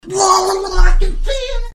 • Качество: 320, Stereo
веселые
короткие
смешные
голосовые